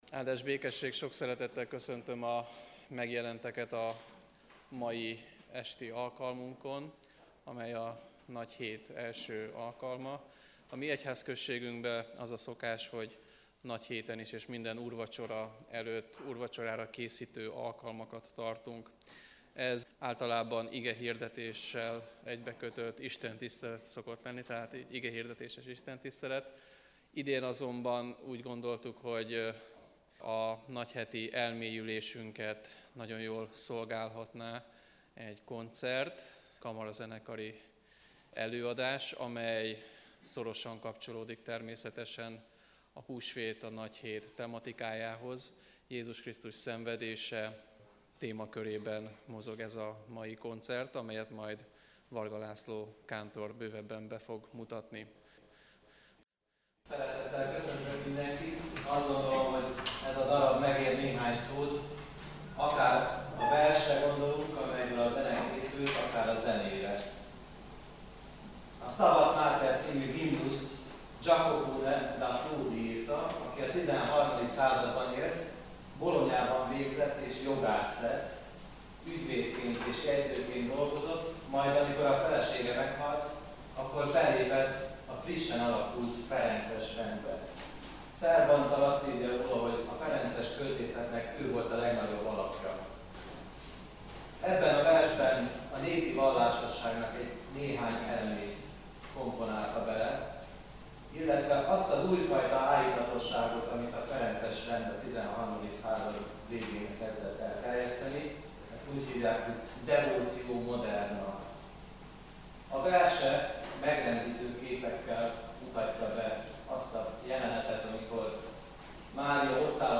2009 április 06, hétfő délután; bűnbánati Istentisztelet